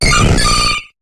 Cri de Leuphorie dans Pokémon HOME.